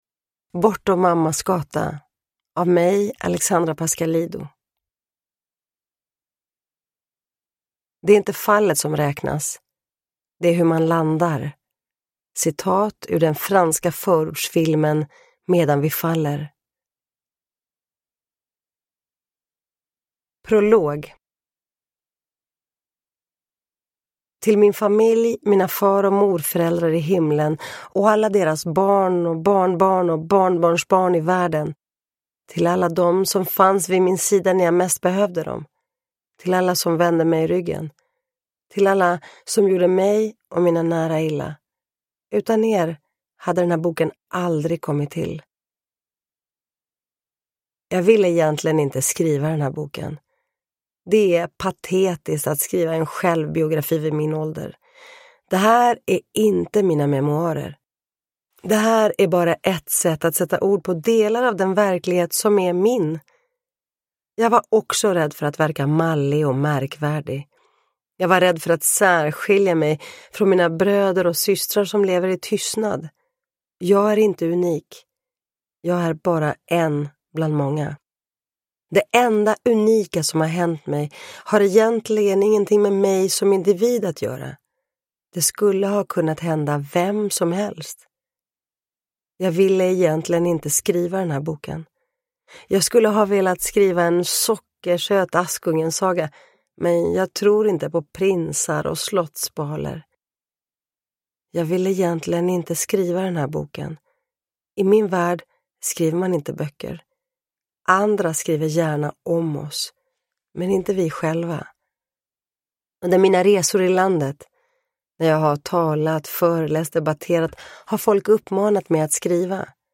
Bortom mammas gata – Ljudbok – Laddas ner
Uppläsare: Alexandra Pascalidou